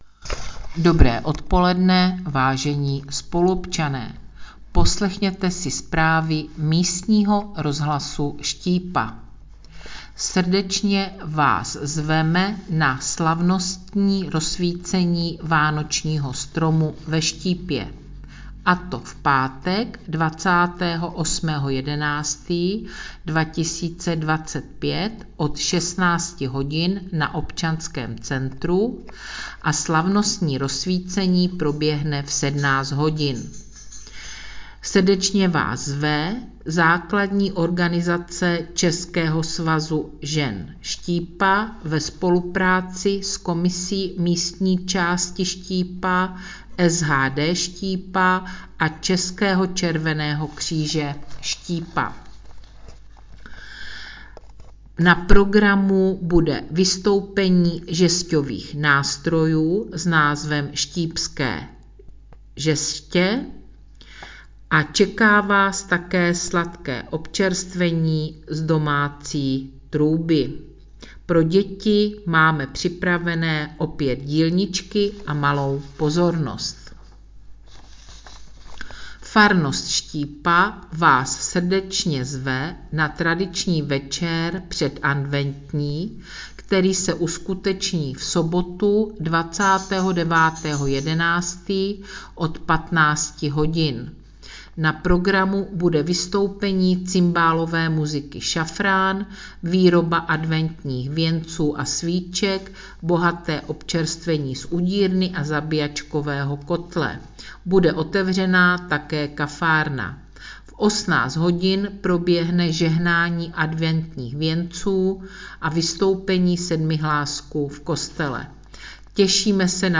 Hlášení místního rozhlasu